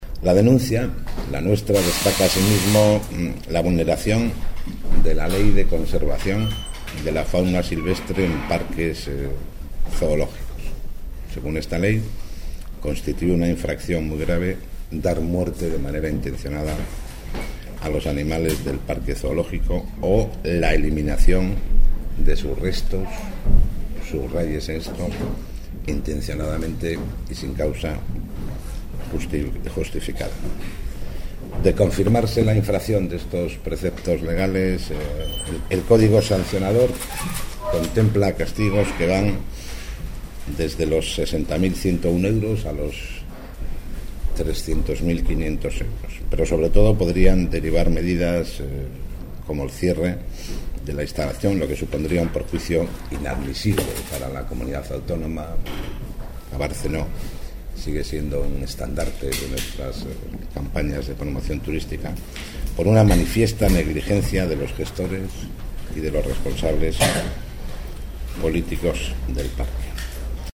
Javier L?pez Marcano en la rueda de prensa de hoy